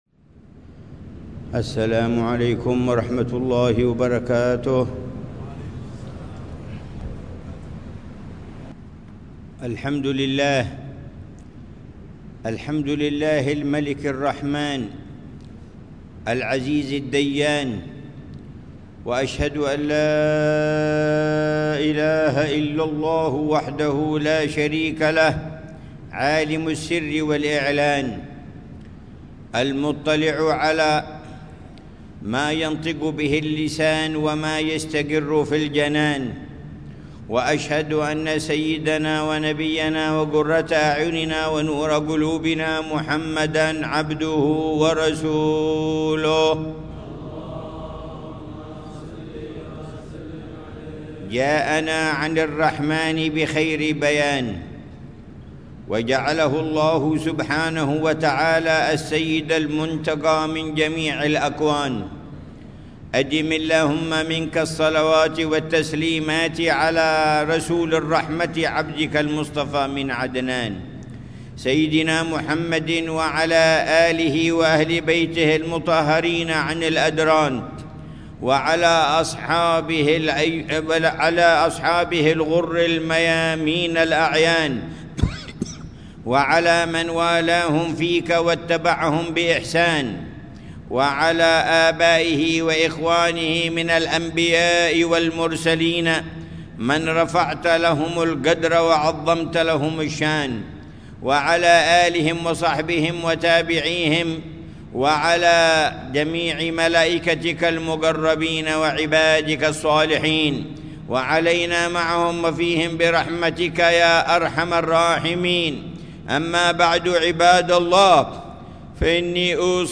خطبة الجمعة للعلامة الحبيب عمر بن محمد بن حفيظ في جامع الإيمان، بحارة الإيمان، عيديد، تريم، 13 شوال 1446هـ بعنوان: